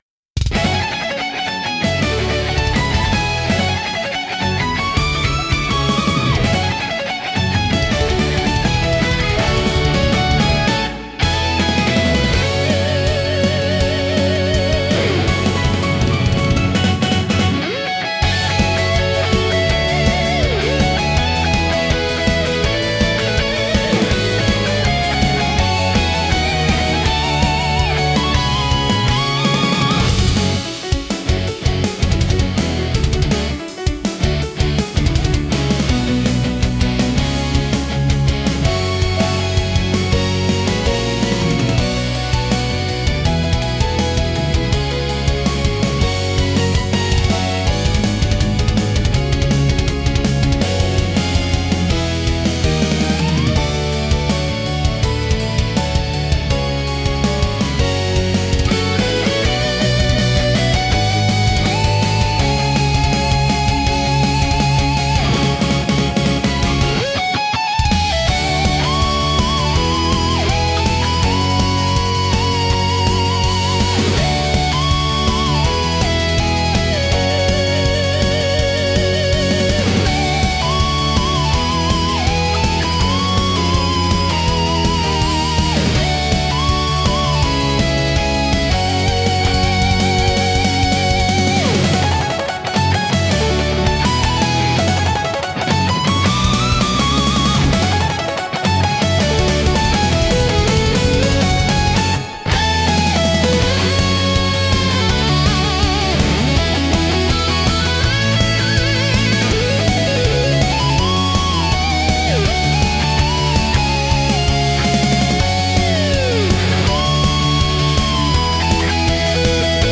BGMとしては、ゲームや映像の戦闘シーンに最適で、激しいアクションと感情の高まりを盛り上げます。